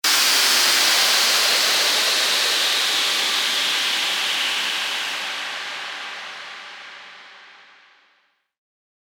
FX-1894-WHOOSH
FX-1894-WHOOSH.mp3